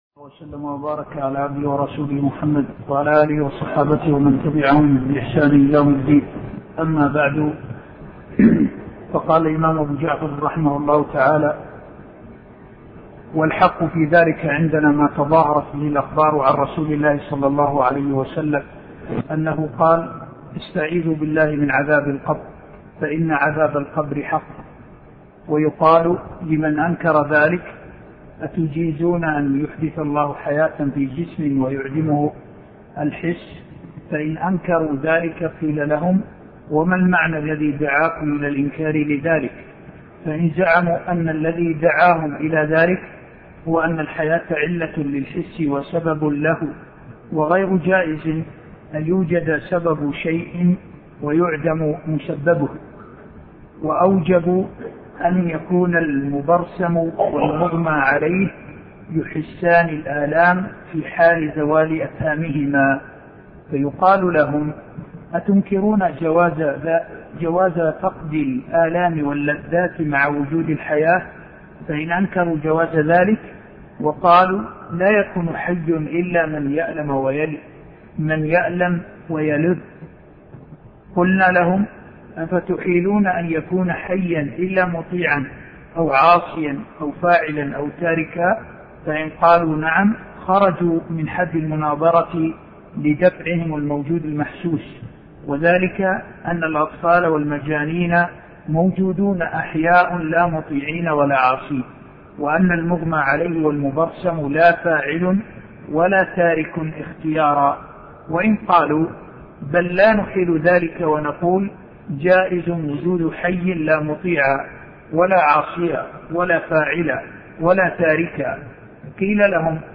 عنوان المادة الدرس (10)شرح كتاب التبصير في معالم الدين لابن جرير الطبري تاريخ التحميل السبت 31 ديسمبر 2022 مـ حجم المادة 40.23 ميجا بايت عدد الزيارات 212 زيارة عدد مرات الحفظ 92 مرة إستماع المادة حفظ المادة اضف تعليقك أرسل لصديق